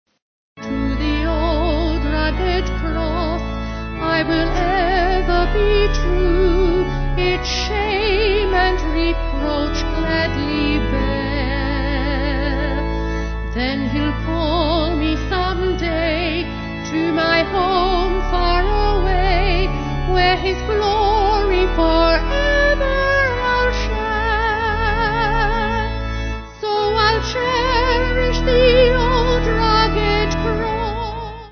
Vocals & Organ